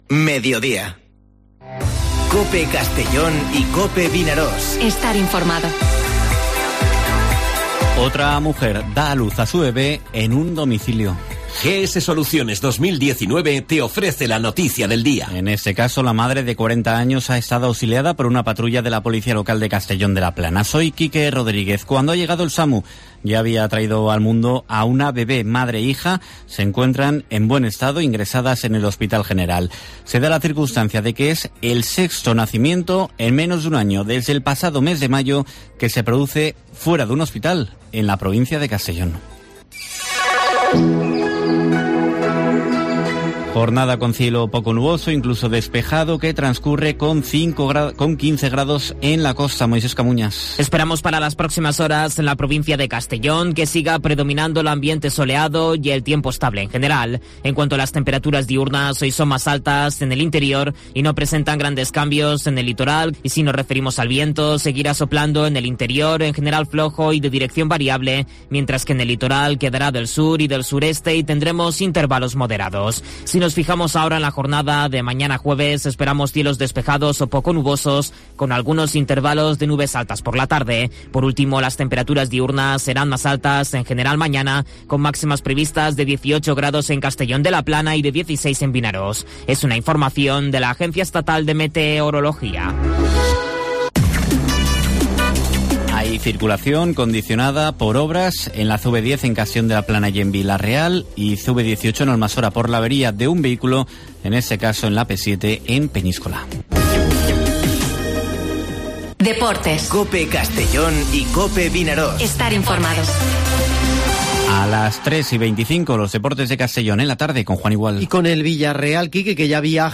Informativo Mediodía COPE en la provincia de Castellón (10/03/2021)